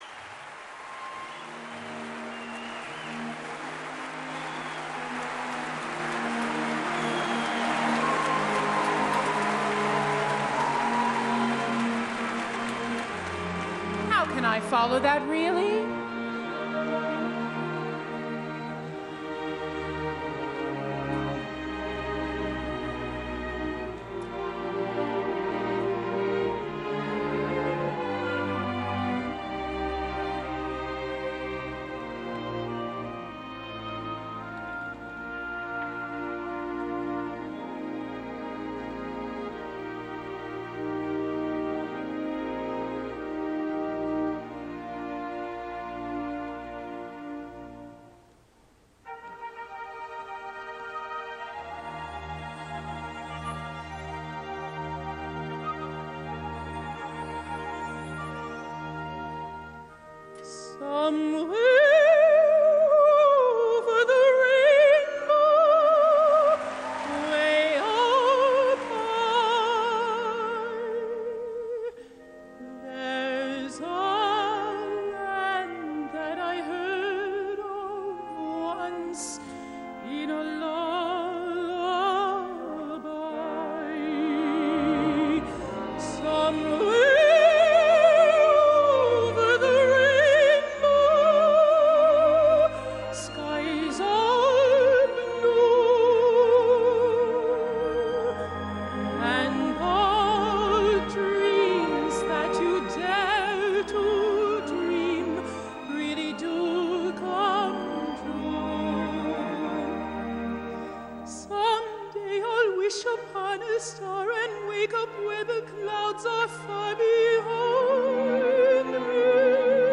la soprano nord-americana